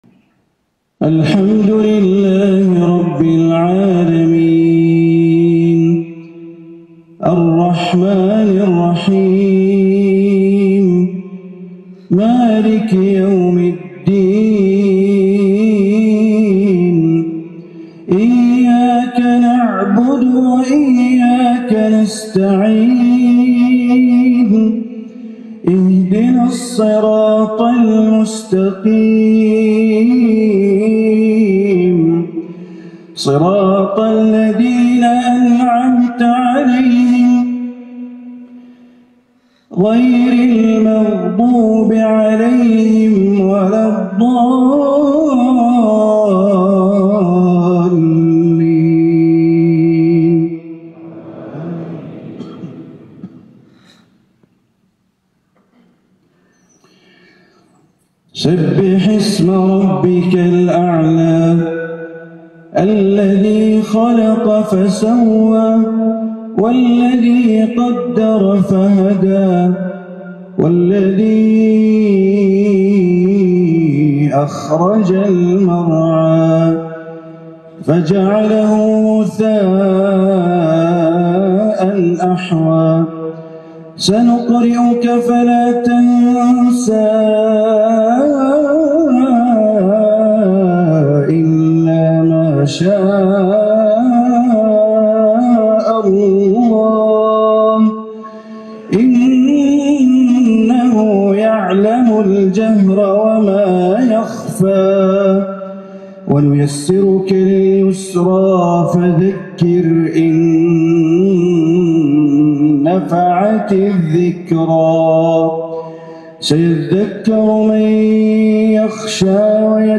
صلاة الجمعة من كوسوفو من المسجد الكبير في بريشتينا | ١١ ذو القعدة ١٤٤٦ هـ > زيارة الشيخ بندر بليلة الى جمهورية كوسوفو > تلاوات وجهود أئمة الحرم المكي خارج الحرم > المزيد - تلاوات الحرمين